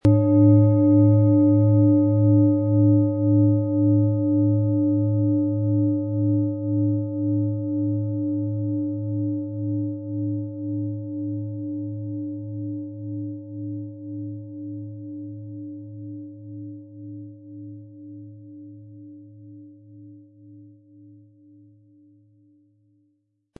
• Mittlerer Ton: Chiron
• Höchster Ton: Alphawelle
Wie klingt diese tibetische Klangschale mit dem Planetenton Mond?
Durch die traditionsreiche Herstellung hat die Schale stattdessen diesen einmaligen Ton und das besondere, bewegende Schwingen der traditionellen Handarbeit.
MaterialBronze